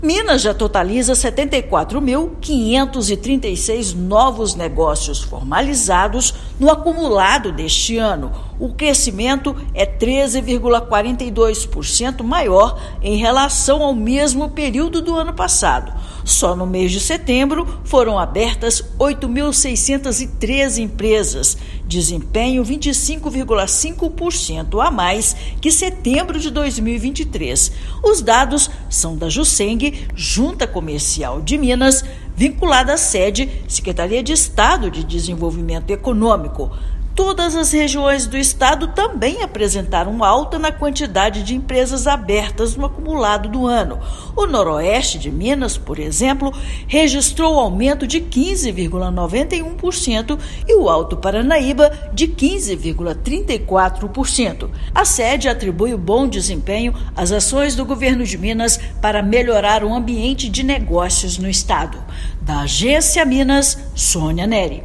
Com mais de 8 mil negócios abertos em setembro, volume é 25,54% maior do que o registrado no mesmo mês do ano passado. Ouça matéria de rádio.